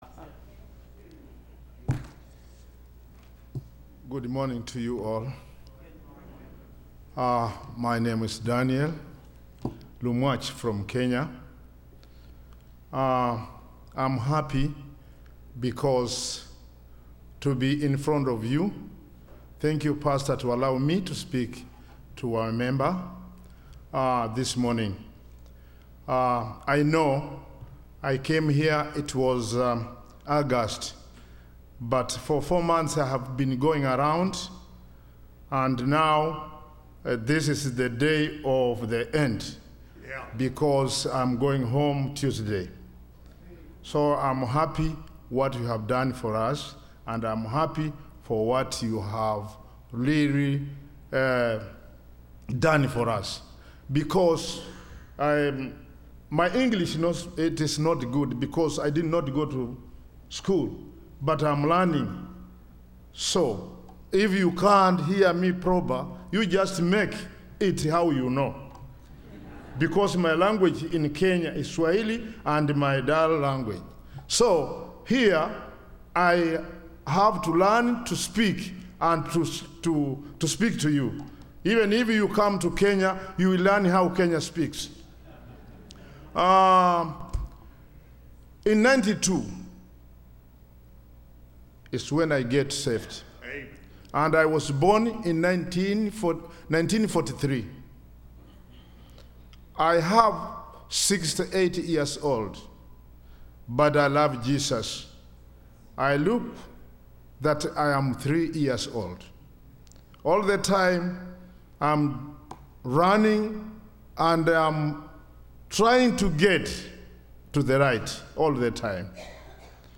Service Type: Sunday School Missionary